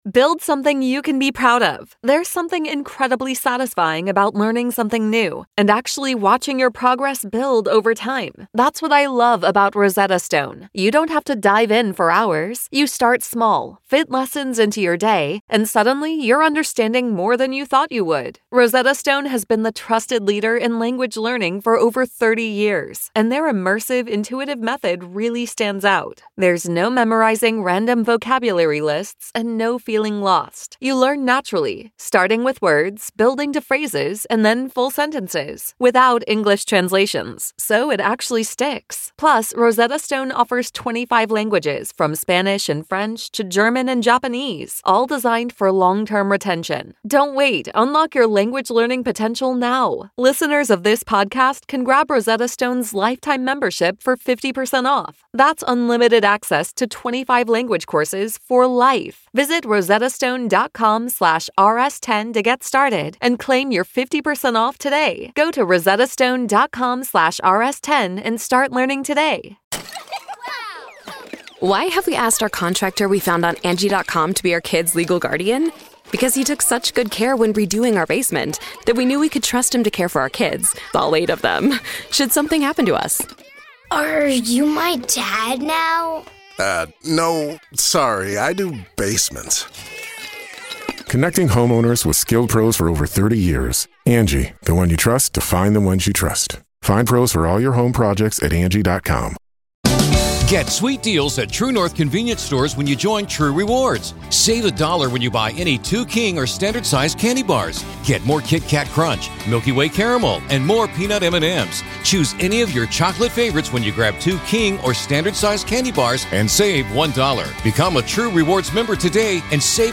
This is our daily Tech and Business report.